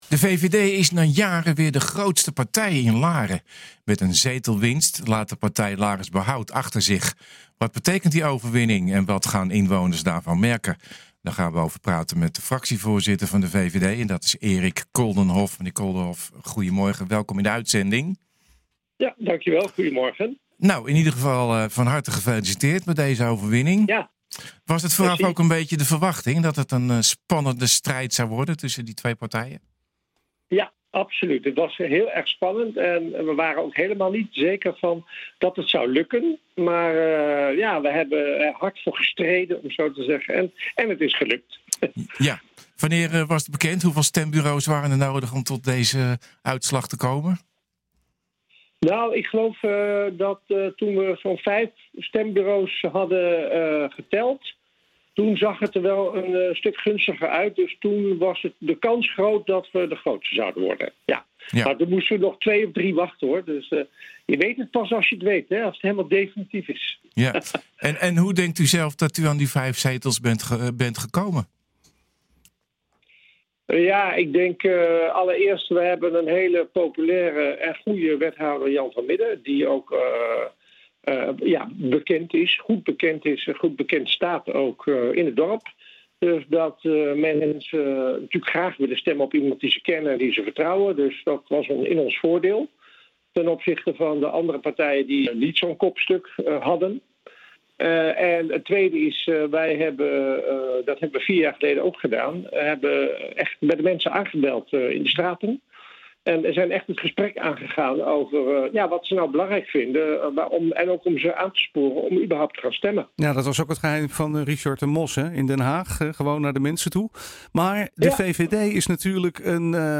Wat betekent die overwinning en wat gaan inwoners daarvan merken? Daarover praten wij met fractievoorzitter Erik Koldenhof.